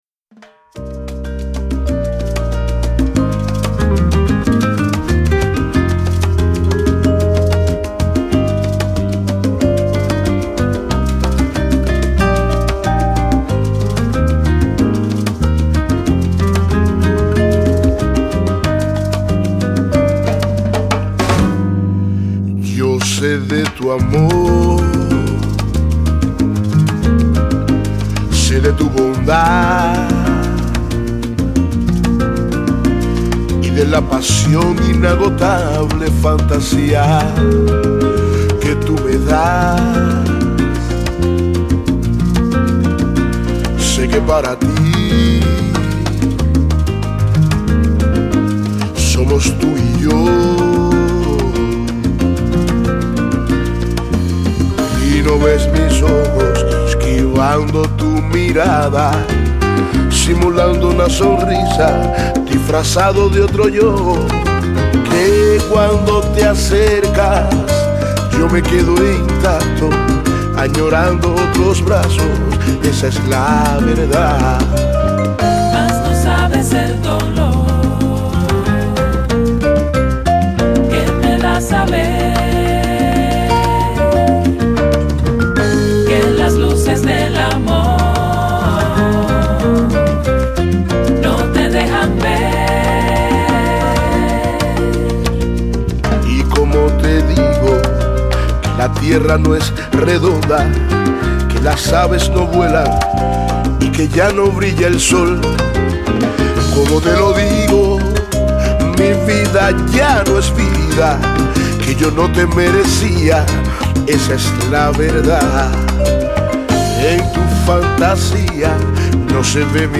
Но песня классная) Прям мурашки под кожей и те поют.